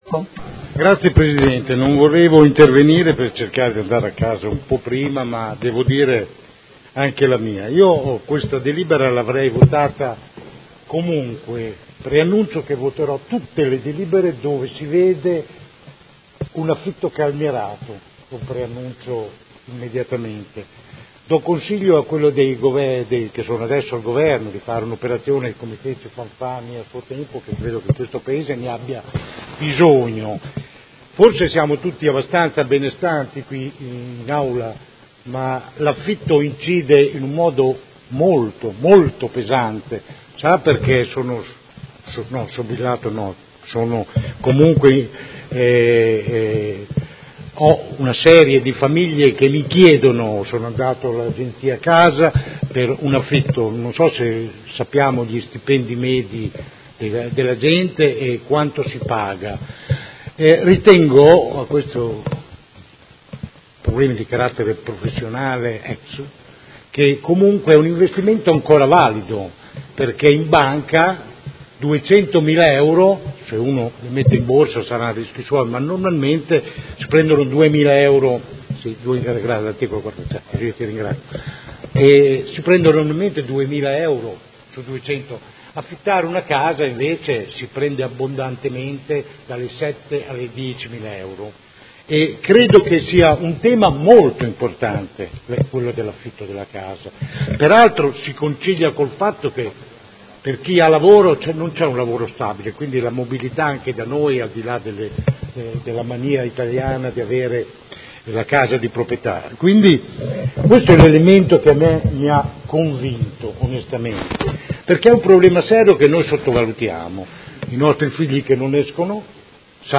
Seduta del 19/07/2018 Dibattito.